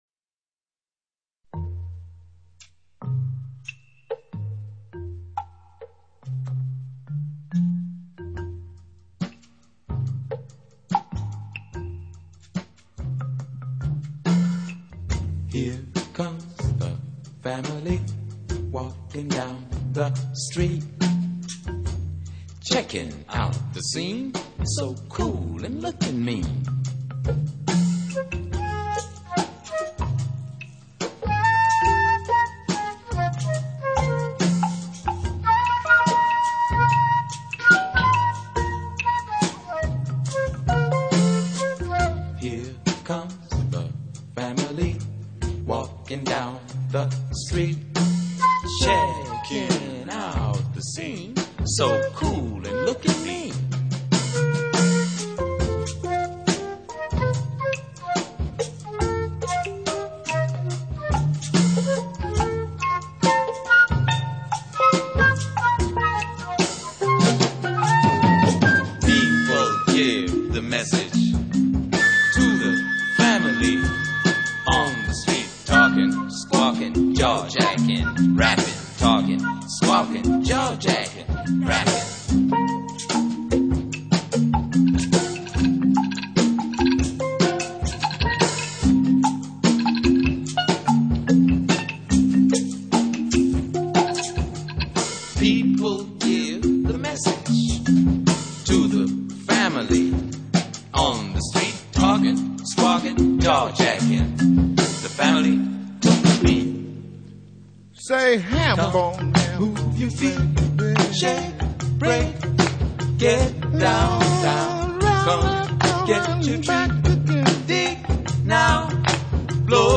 drums
marimba
steel drum